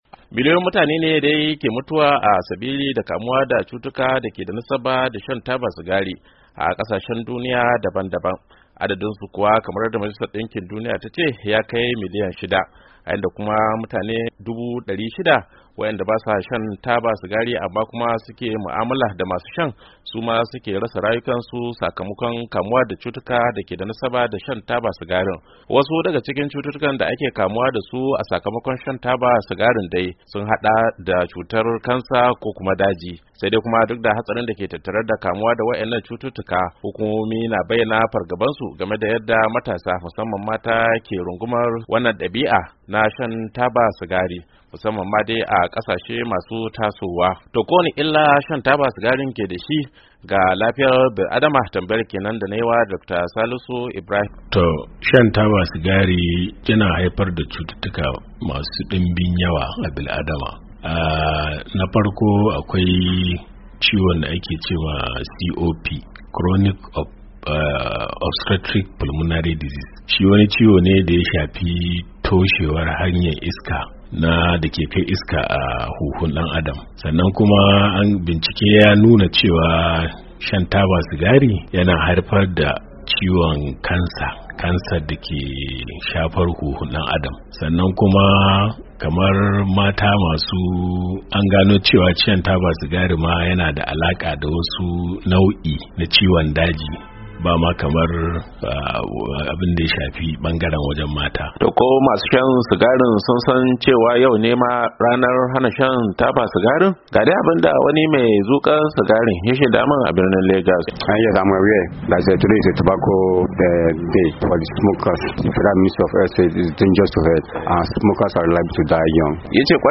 Wani rahoton hukumar lafiya ta majalisar dinkin duniya, ya nuna cewar mashaya taba sigari da wadanda ke shakar hayakin taba kimanin mutane miliyan 8 ke mutuwa a duk shekara, sakamakon kamuwa da cututtukan dake da alaka da shan tabar ko shakan hayakinta. A wata tattaunawa